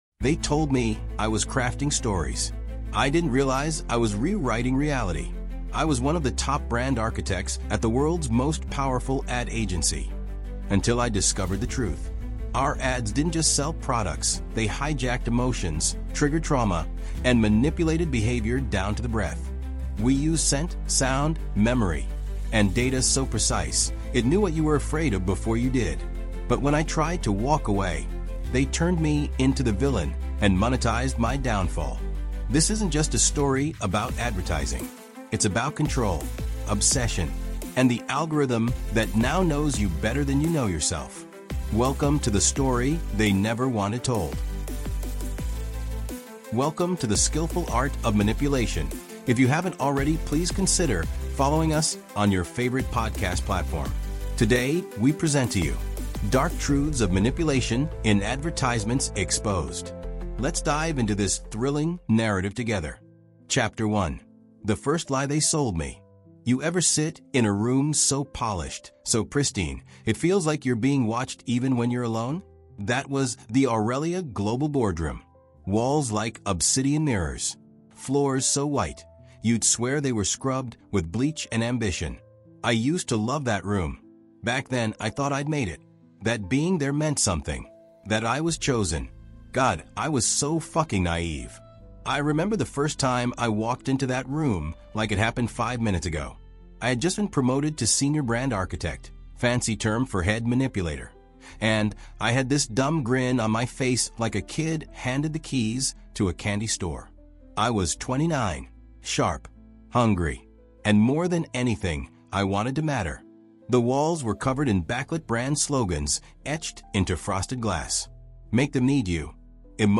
Dark Truths of Manipulation in Advertisements Exposed | Audiobook